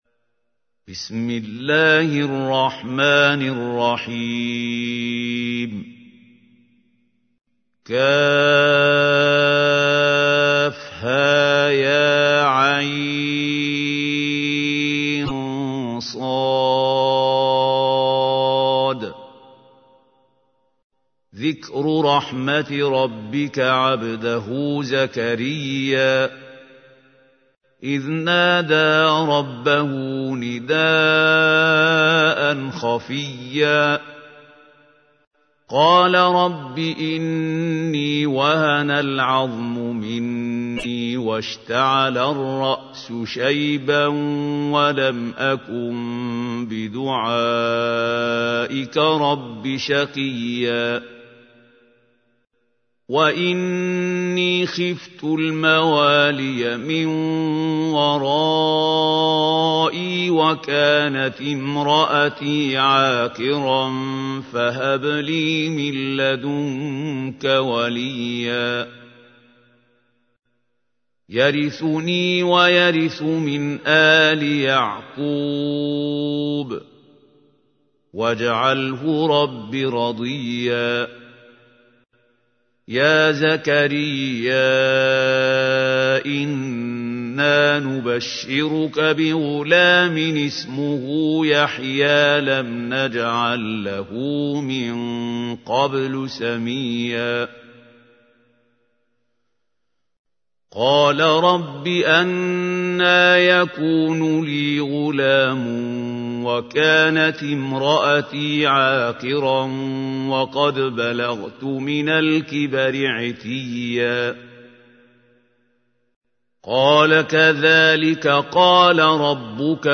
تحميل : 19. سورة مريم / القارئ محمود خليل الحصري / القرآن الكريم / موقع يا حسين